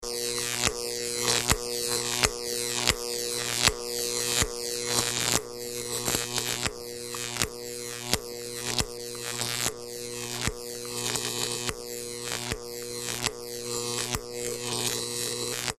Jacob's Ladder 1; Repetitive Electrical Build Up And Discharge; Close Perspective. Science Lab, Spark, Arc.